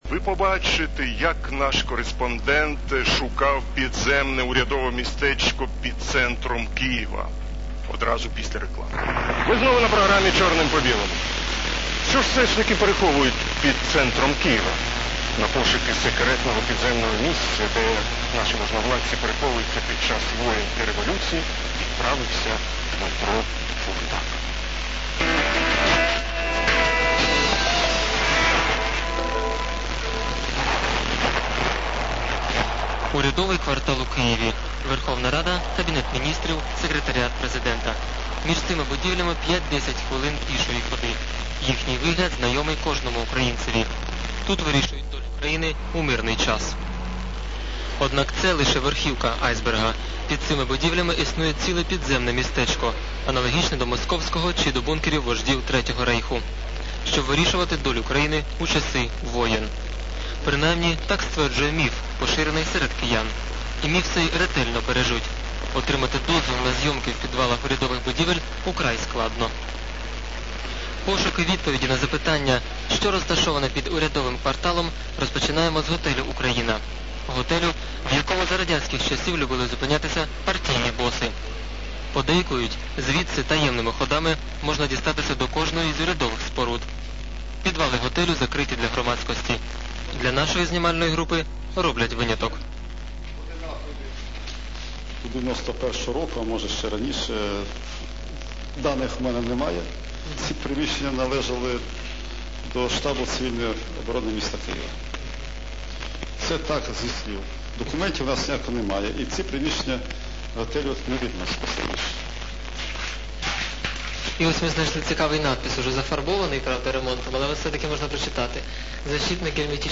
Аудио 1,72 Мб / потоковое аудио (записано подручными средствами ;) ).